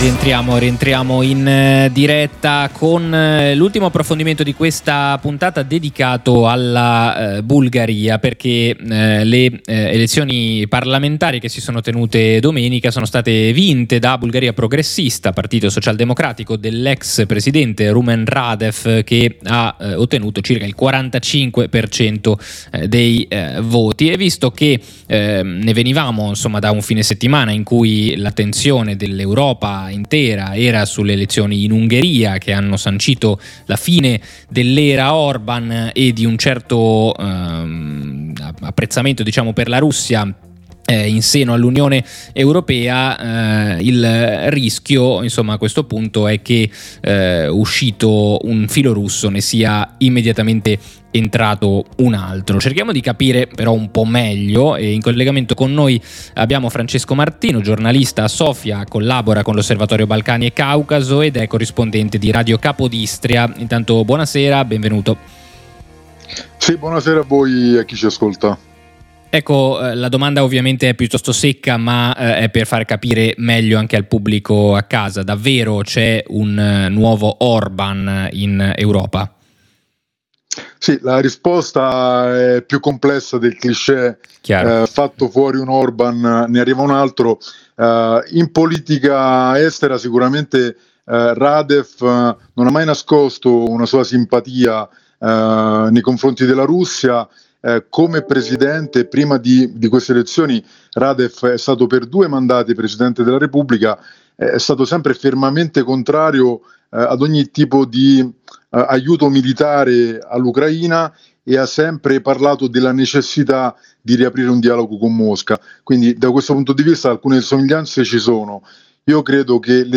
La vittoria di Rumen Radev alle elezioni politiche del 19 aprile è legata soprattutto alla promessa di risolvere l’instabilità politica in cui la Bulgaria versa da cinque anni. L’analisi